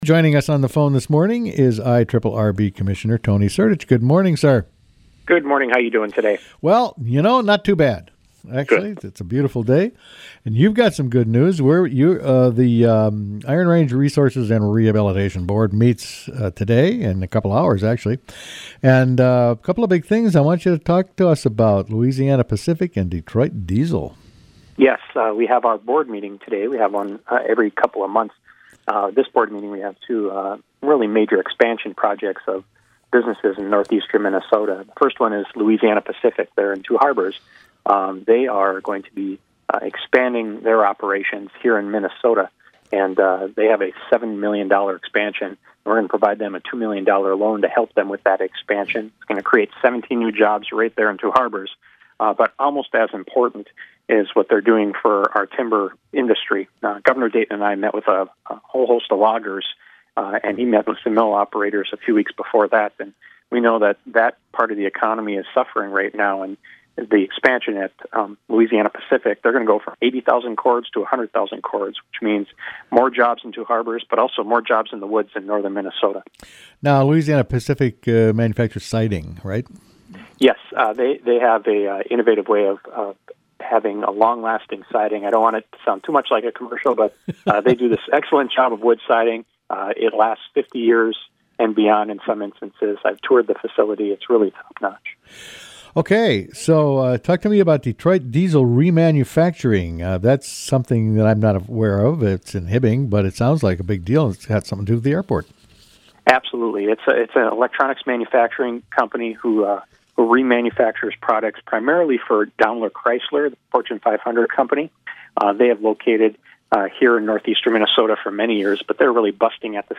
spoke with Iron Range Resources and Rehabilitation Board Commissioner Tony Sertich about the good news.